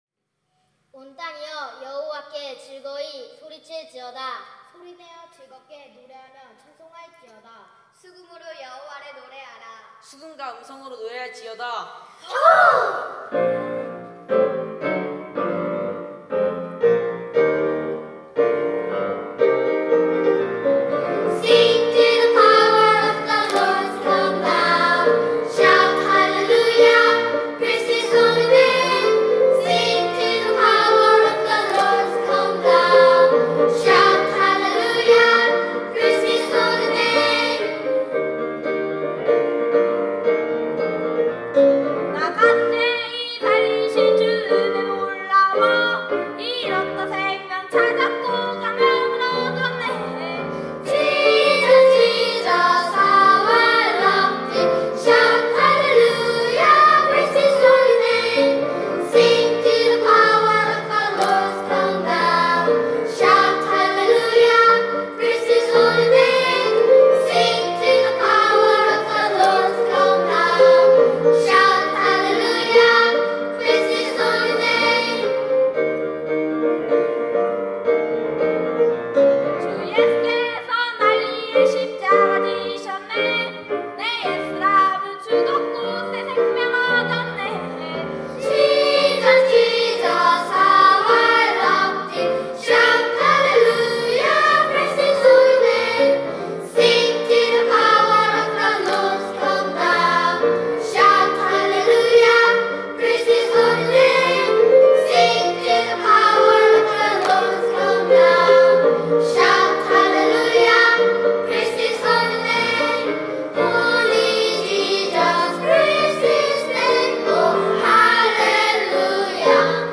특송과 특주 - Praise His Holy name